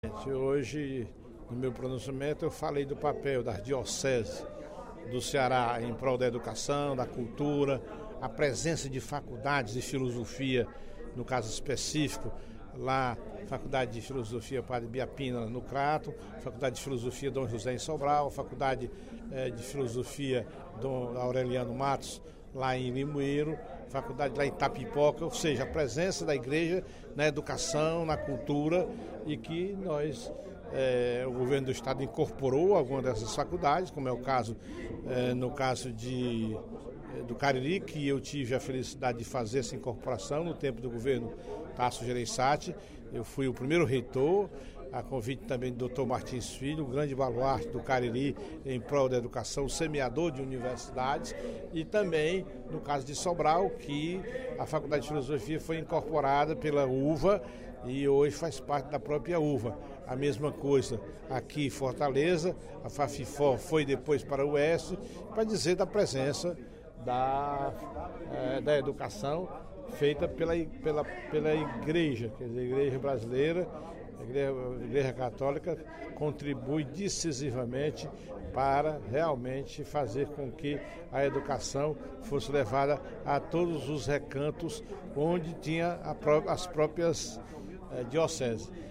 No primeiro expediente da sessão plenária desta quarta-feira (14/05), o deputado Professor Teodoro (PSD) destacou o papel da Igreja Católica na educação e cultura do Ceará.